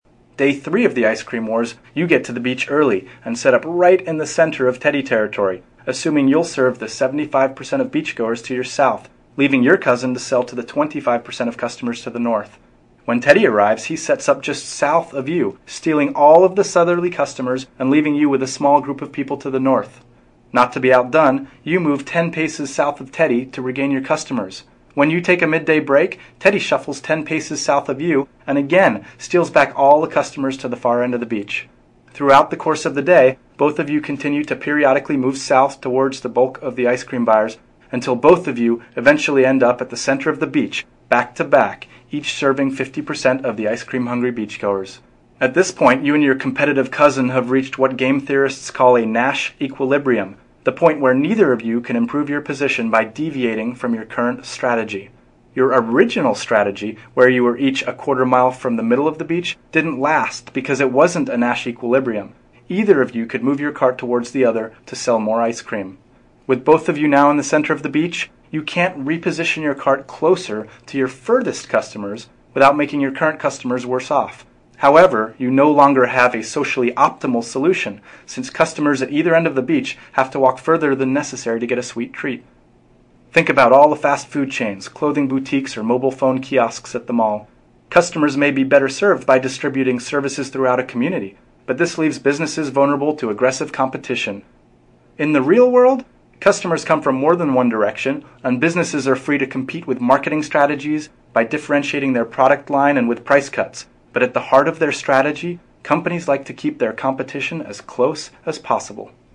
TED演讲:为什么竞争者要把店开在彼此旁边(2) 听力文件下载—在线英语听力室